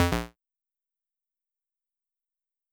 game_over.wav